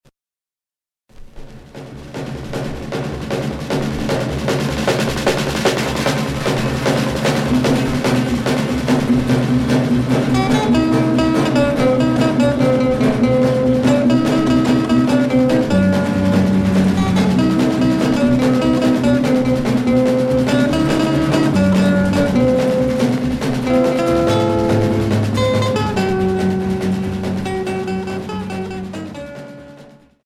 Rock 60's Unique EP retour à l'accueil